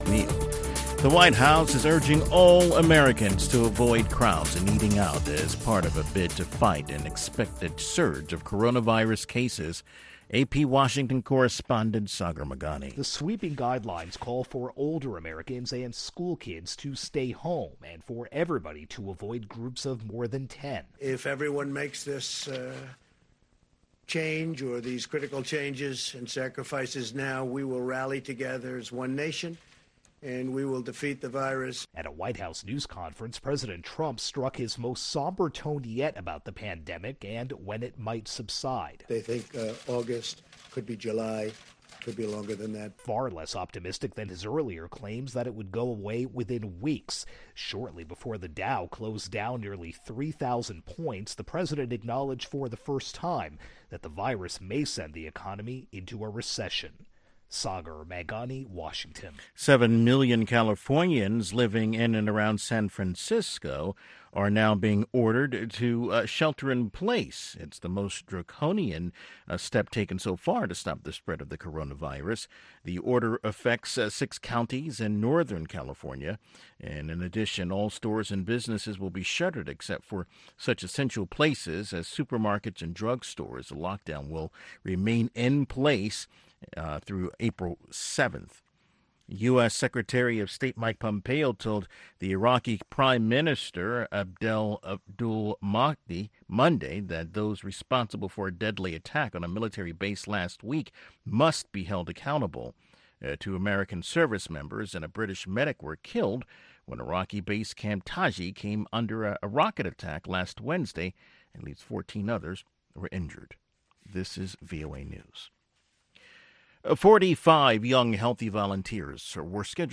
African Beat showcases the latest and the greatest of contemporary African music and conversation. From Benga to Juju, Hip Life to Bongo Flava, Bubu to Soukous and more